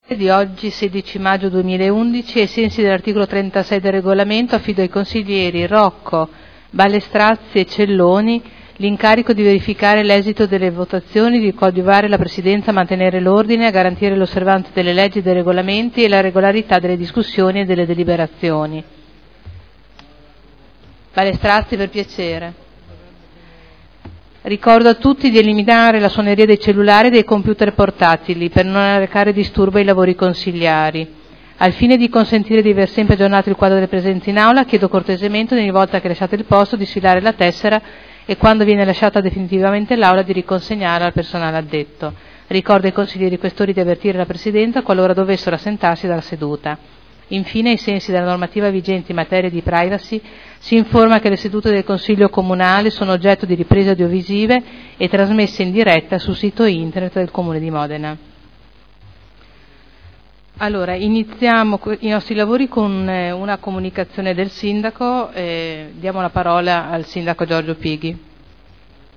Seduta del 16/05/2011. Apertura lavori